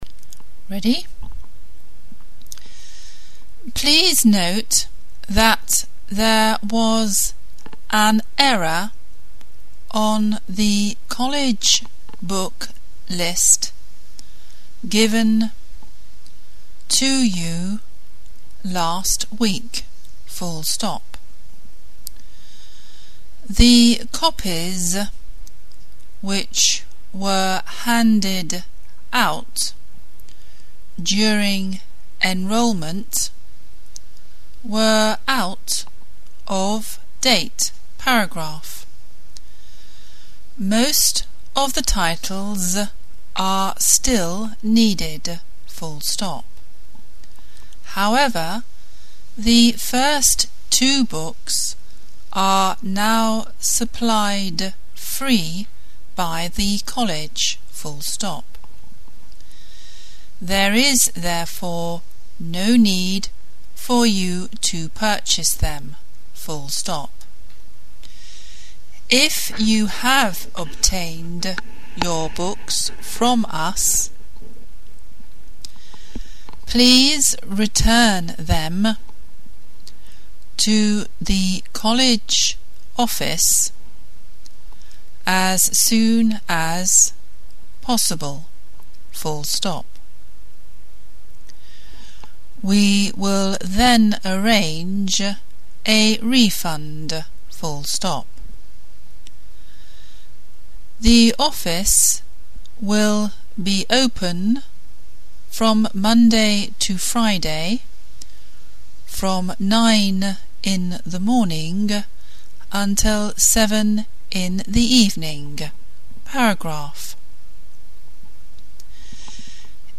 Exam Dictation
Below you will find the dictation for your Shorthand Speed Exam at 60 wpm.
Dictation-Exam-60-wpm.mp3